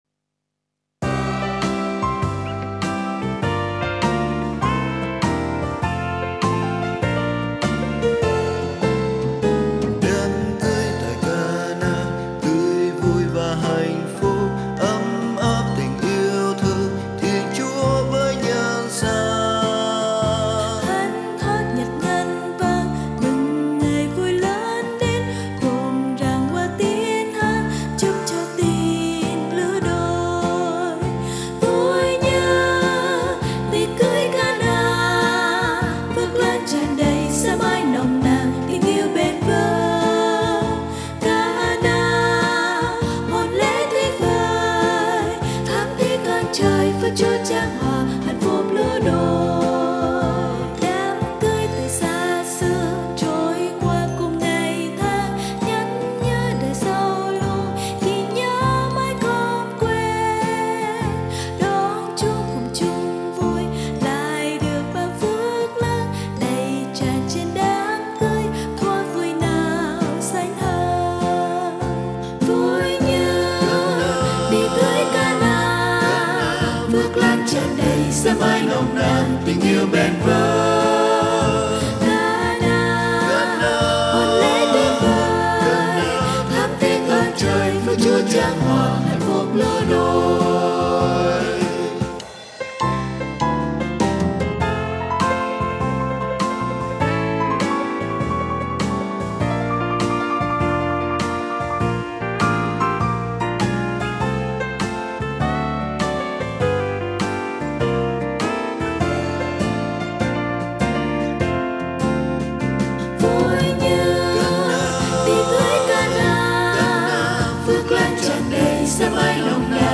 Nhạc Thánh Sáng Tác Mới